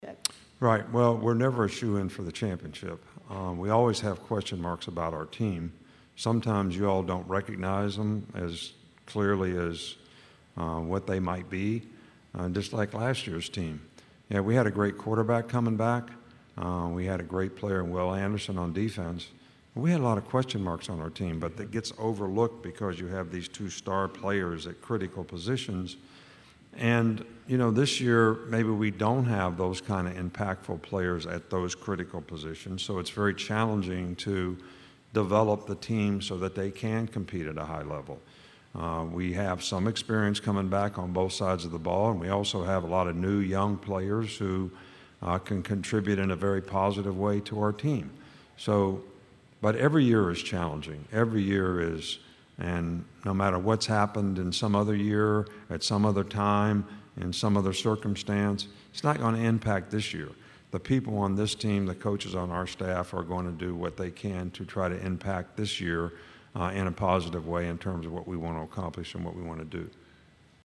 During his time on the podium, Saban talked quarterback disputes, player development, staff changes and additions and more on what’s to come this season.